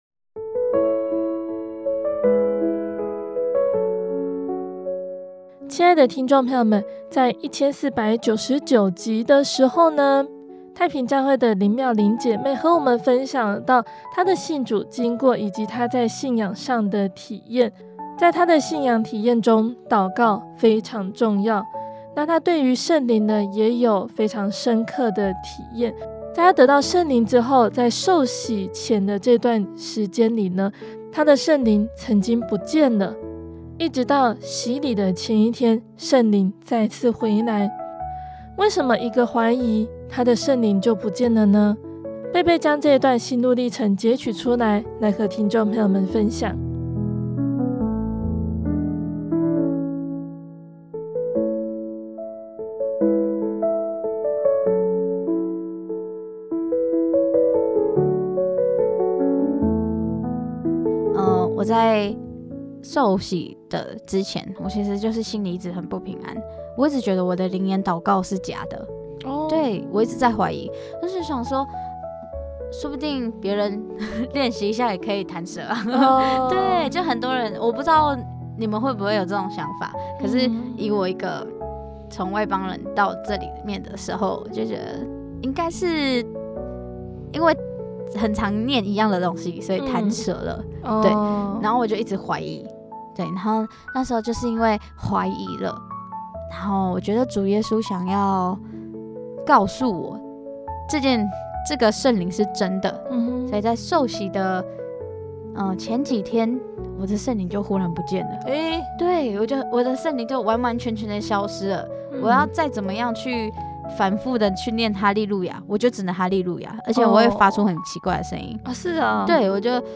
訪